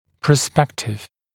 [prə’spektɪv][прэ’спэктив]потенциальный, будущий